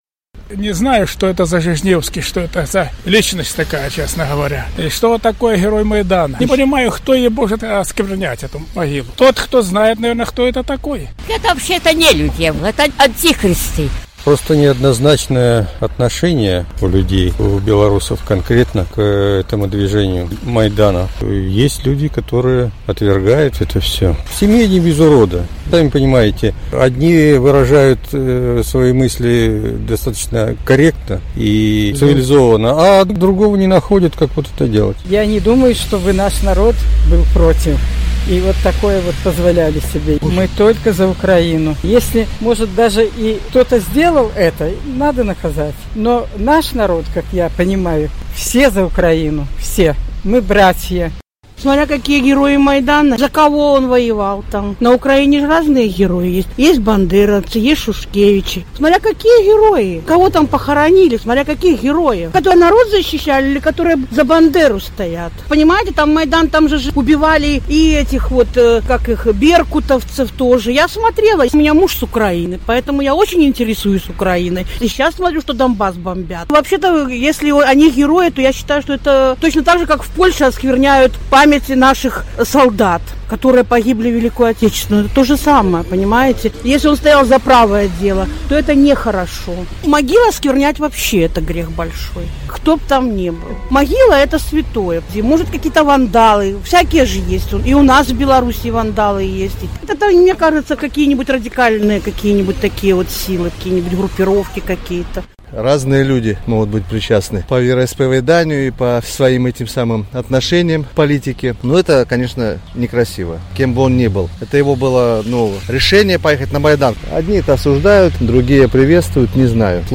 На гэтае пытаньне адказваюць жыхары Гомеля.